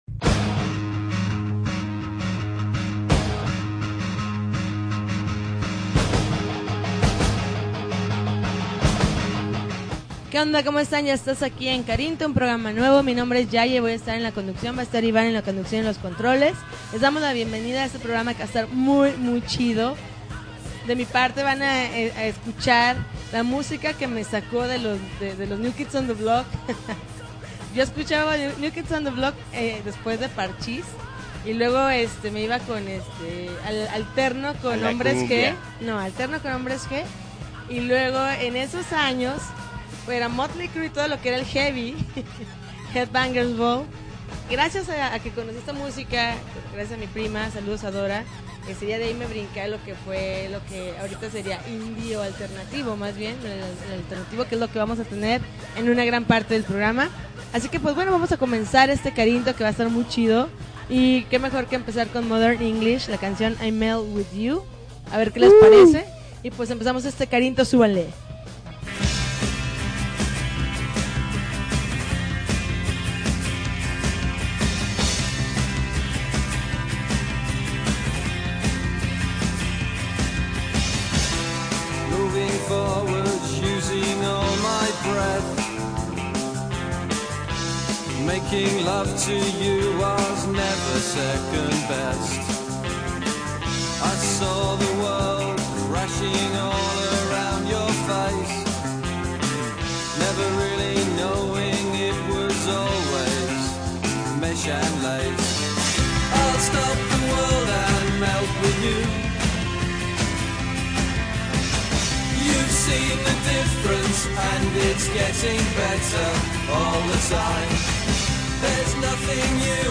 September 26, 2010Podcast, Punk Rock Alternativo